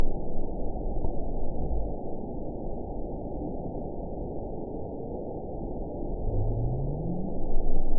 event 921108 date 04/28/24 time 17:03:07 GMT (1 year ago) score 8.68 location TSS-AB05 detected by nrw target species NRW annotations +NRW Spectrogram: Frequency (kHz) vs. Time (s) audio not available .wav